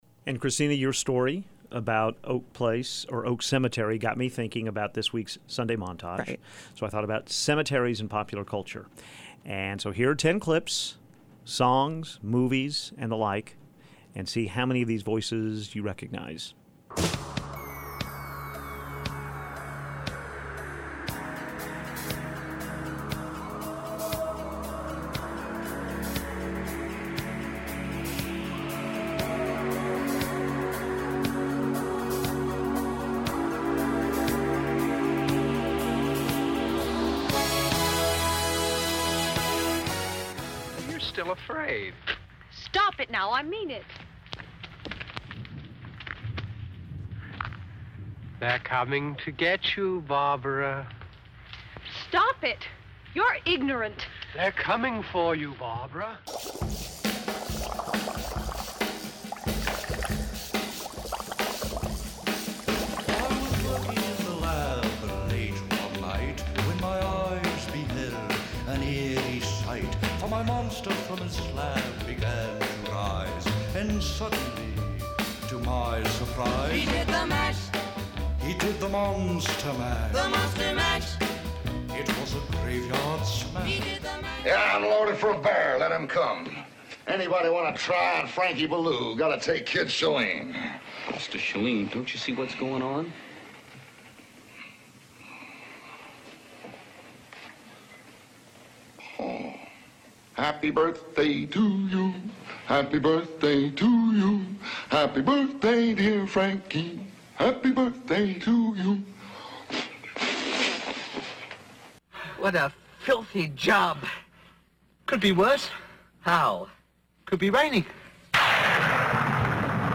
Here is the key to our clips heard in this morning’s montage of famous cemeteries, graveyards and funerals in pop culture: Thriller by Michael Jackson.
Theme from the HBO series Six Feet Under.